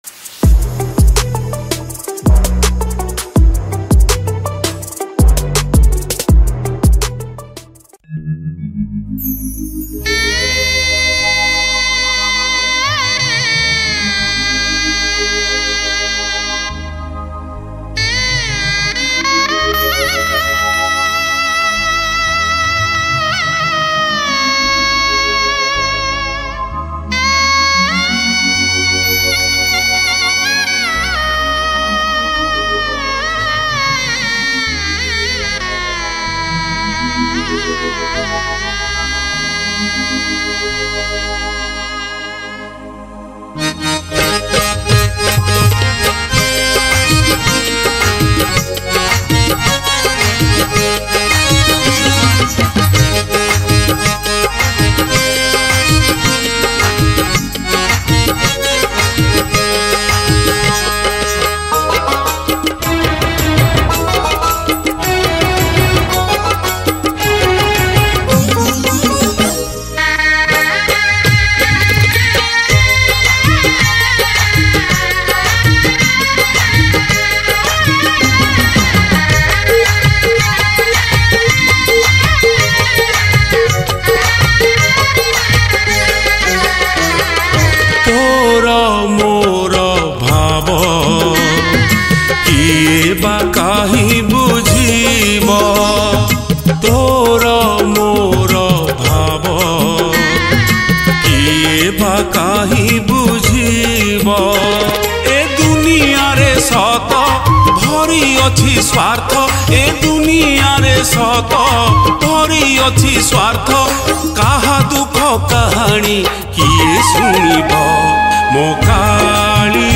Ratha Yatra Odia Bhajan 2022 Songs Download